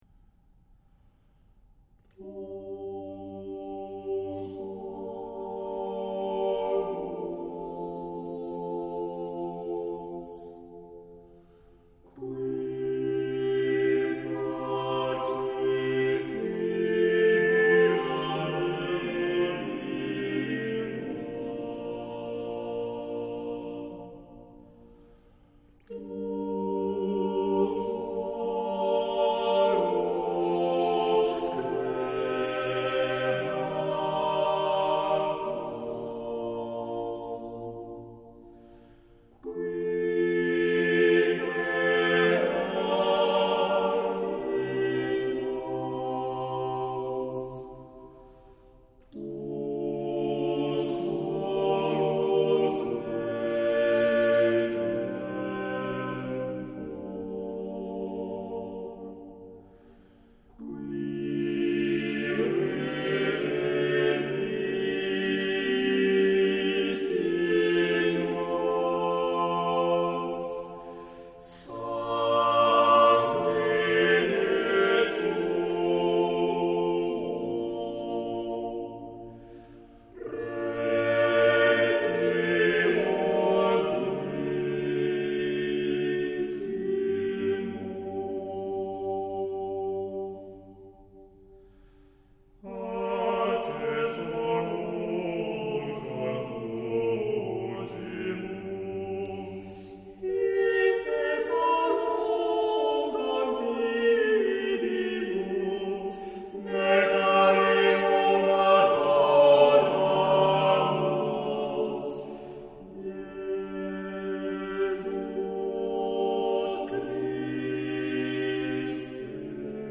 Στο ακόλουθο παράδειγμα συνυπάρχουν τέσσερις φωνές, αλλά είναι πάρα πολύ δύσκολο να ξεχωρίσουμε τις γραμμές τους, γιατί συμβαδίζουν και εκφέρουν το κείμενο ταυτόχρονα (ομοφωνία):